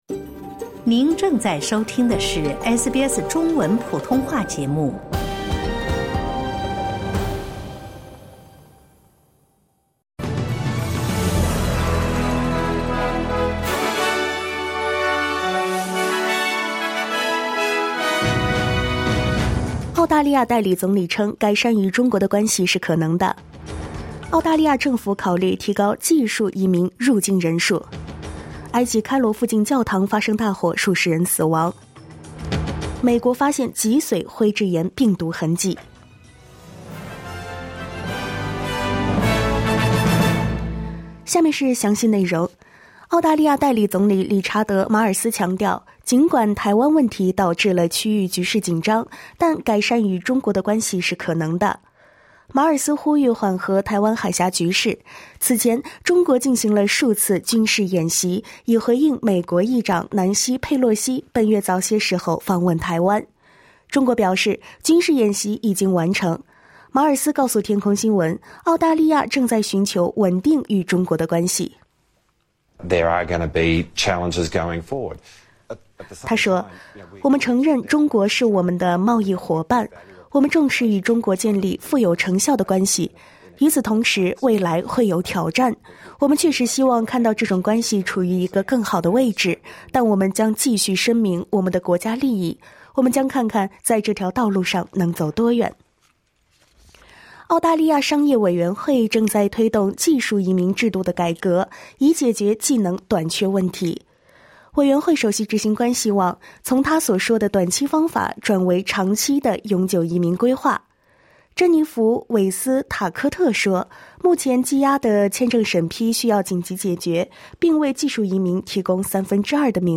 SBS早新闻（8月15日）